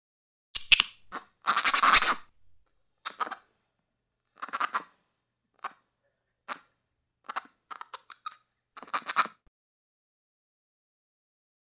Monkey, Capuchin Chatter. High Pitched Chirping Sound. Close Perspective